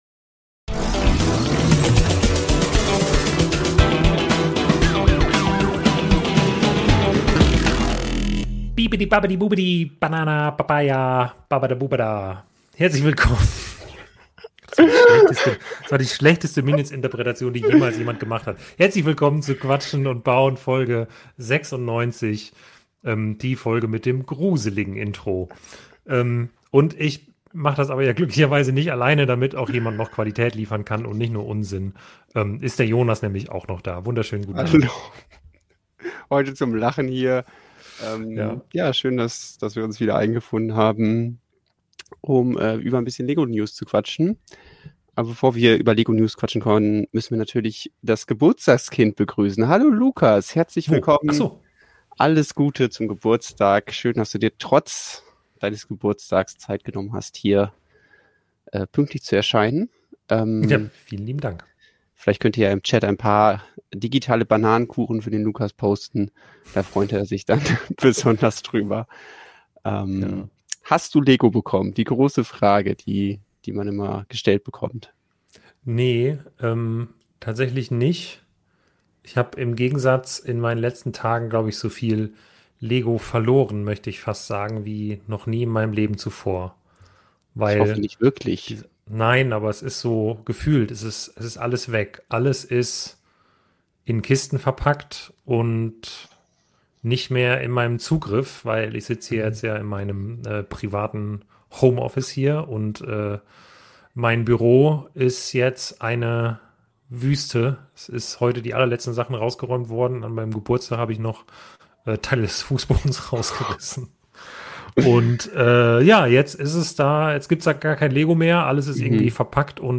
Folge 96 vom LEGO Livestream "Quatschen und Bauen"!